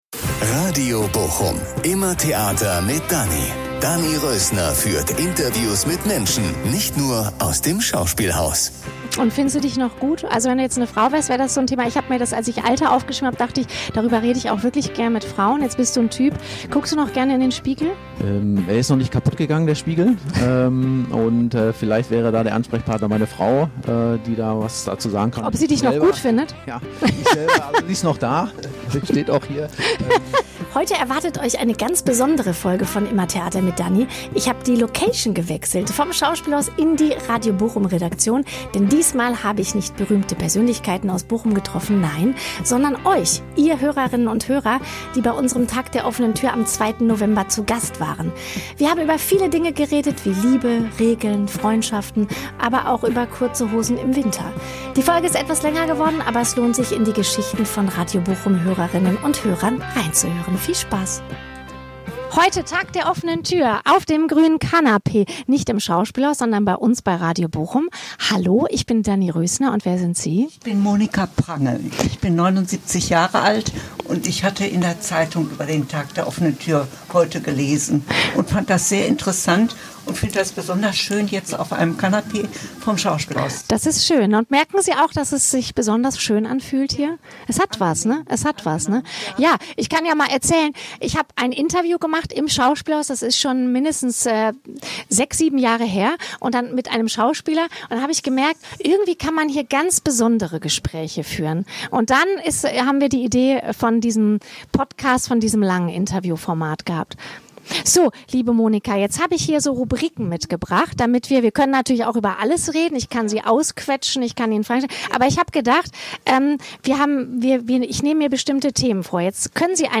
Vom Schauspielhaus direkt in die Radio-Bochum-Redaktion.